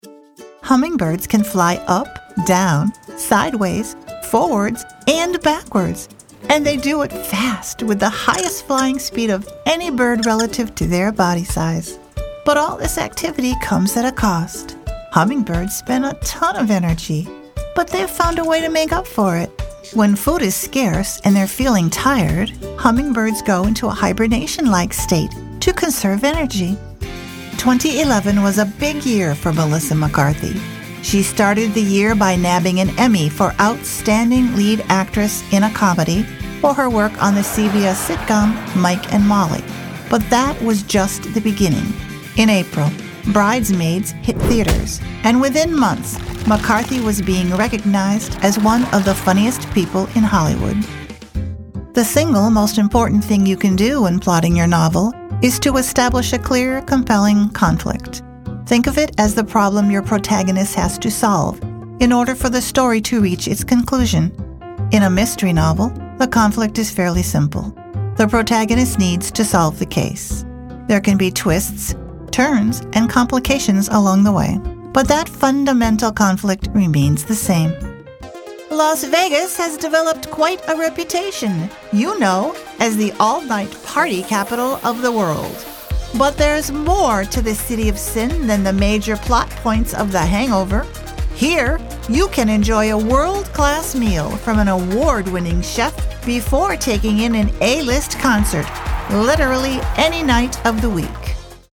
Female
Approachable but never generic — my voice blends friendly warmth with polished clarity.
Narration
Believable.
Words that describe my voice are Believable, friendly, approachable.